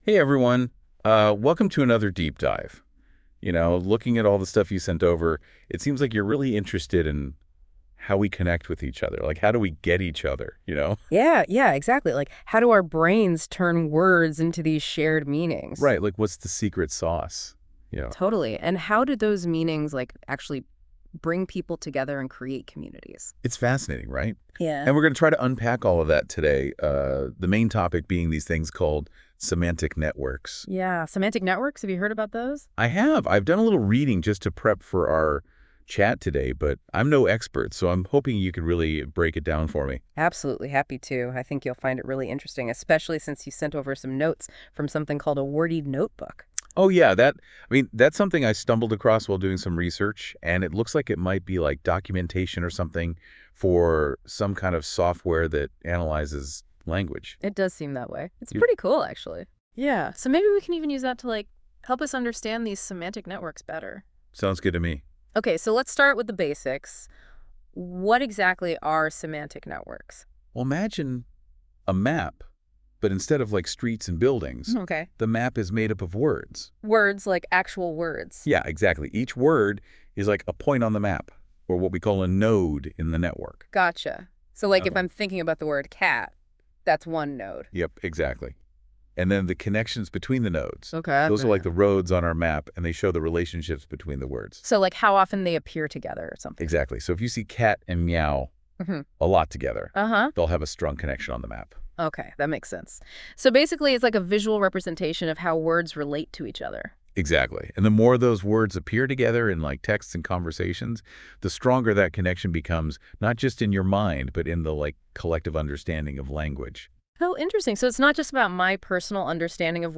Podcast about WORDij and semantic networks Listen to this podcast by Google NotebookLM Note: For detailed documentation for WORDij, once you download it, open the Documentation folder.